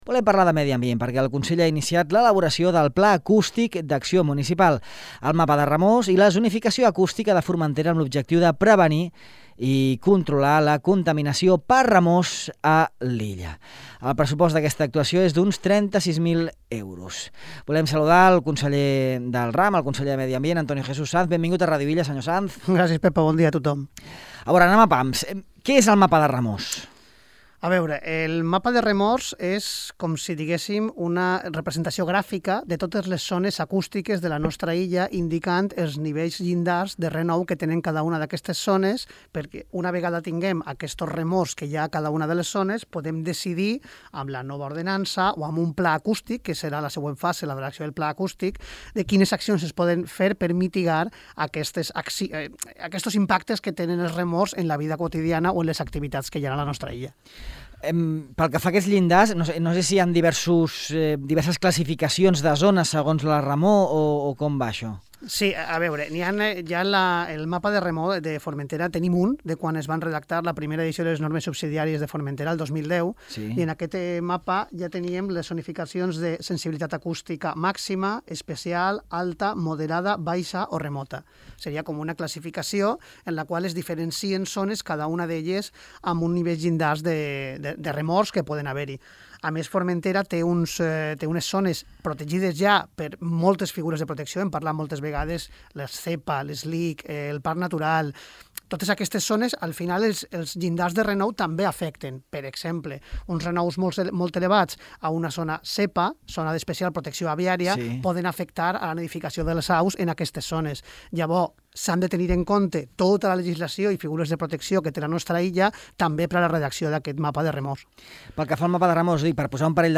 Sanz també ha parlat a Ràdio Illa de la bateria d’actuacions que el Consell de Formentera portarà a terme en matèria de contaminació acústica.